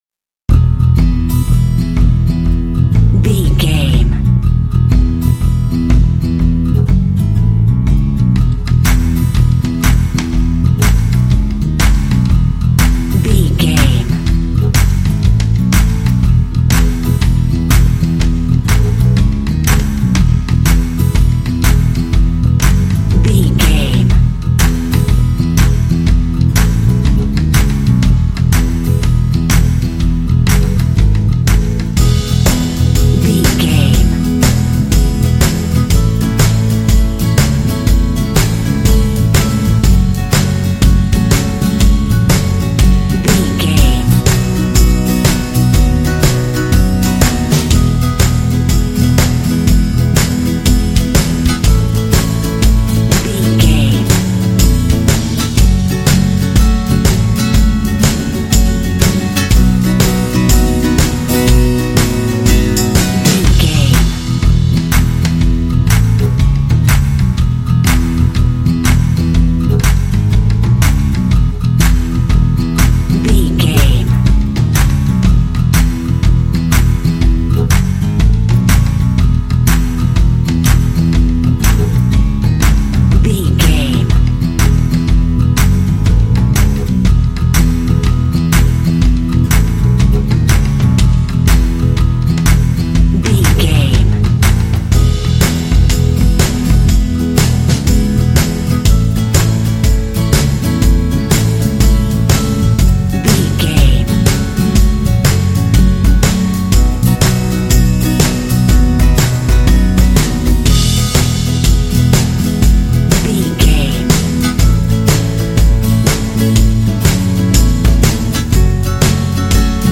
This cheerful, cute folk track has a lead whistle melody.
Uplifting
Ionian/Major
cheerful/happy
bass guitar
acoustic guitar
drums
percussion
indie